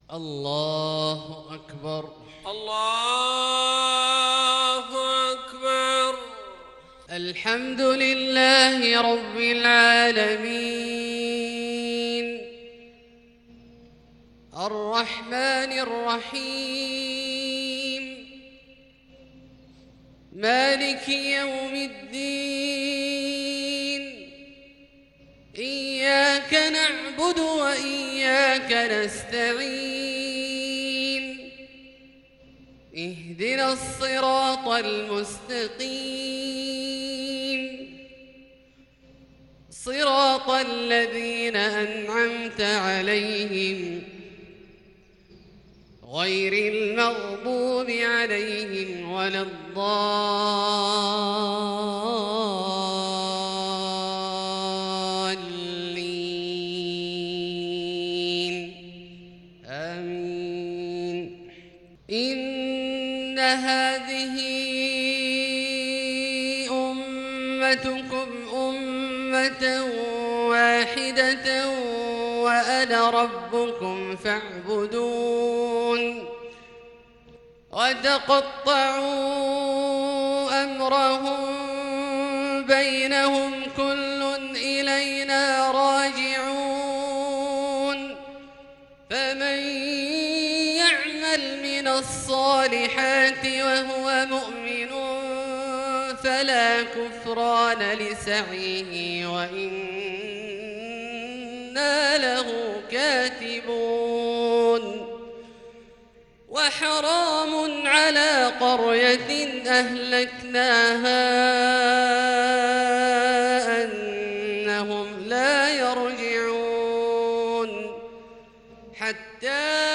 صلاة العشاء للقارئ عبدالله الجهني 6 ربيع الأول 1442 هـ
تِلَاوَات الْحَرَمَيْن .